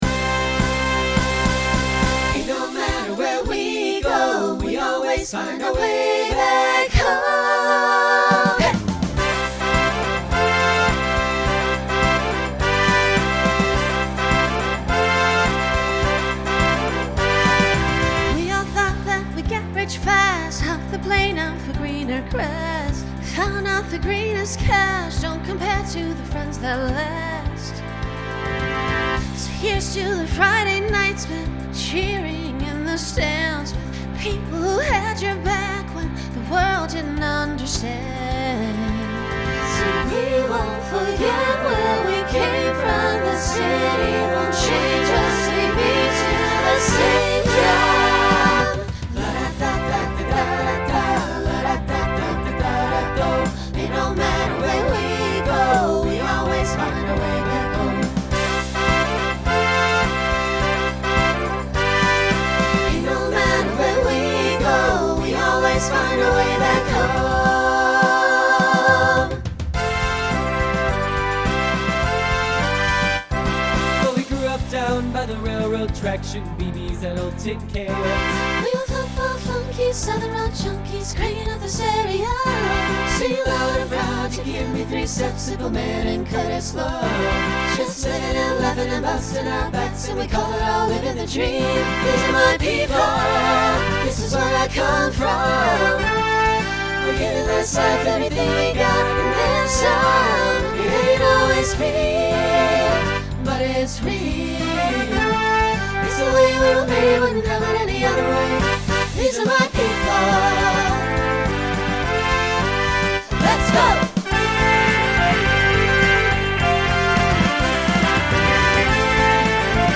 Genre Country Instrumental combo
Voicing SATB